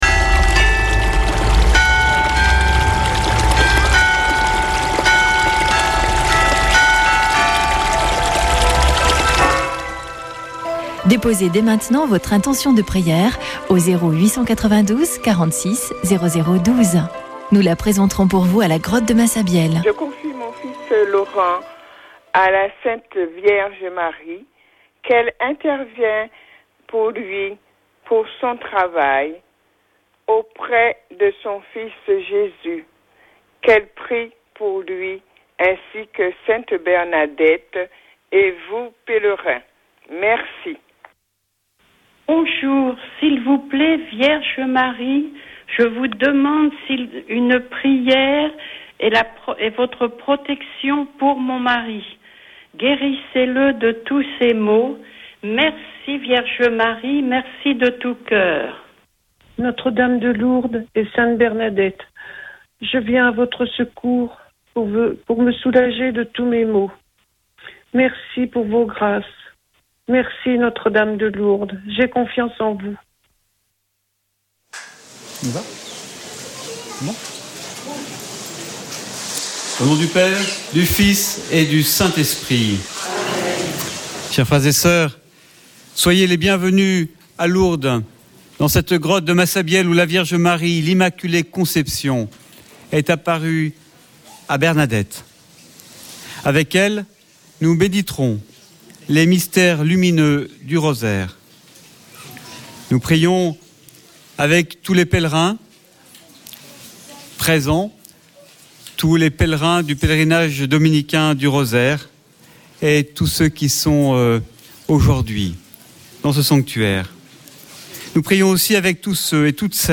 Chapelet de Lourdes du 02 oct.
Une émission présentée par Chapelains de Lourdes